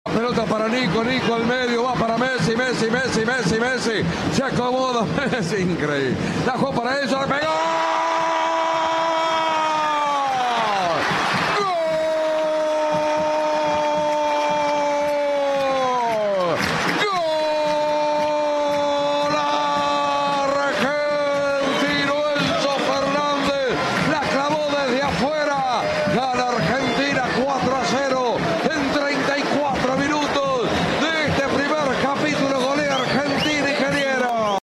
3° Gol de Argentina a Curazao (Lionel Messi) - relato